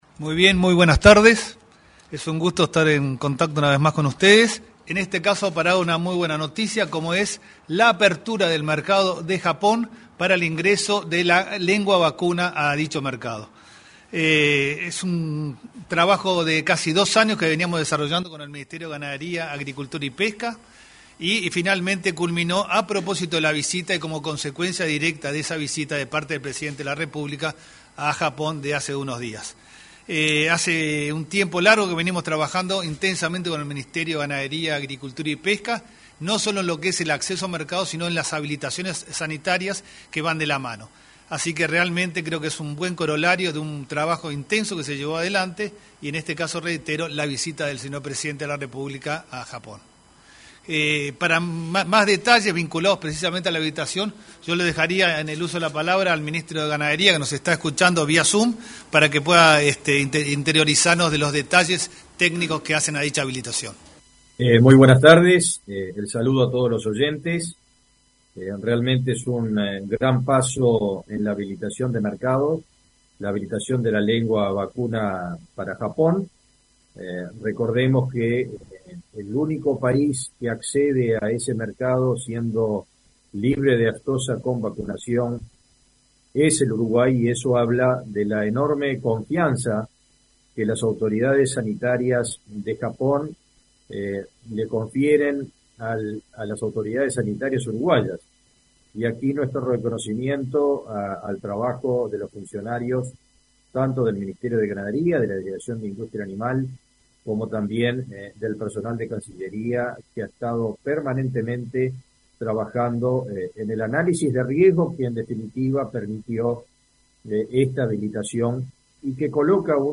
Conferencia de prensa de los ministros de Relaciones Exteriores y Ganadería
Conferencia de prensa de los ministros de Relaciones Exteriores y Ganadería 04/11/2022 Compartir Facebook X Copiar enlace WhatsApp LinkedIn Este viernes 4 de noviembre, los ministros de Relaciones Exteriores, Francisco Bustillo, y Ganadería, Agricultura y Pesca, Fernando Mattos, realizaron una conferencia de prensa en la Torre Ejecutiva.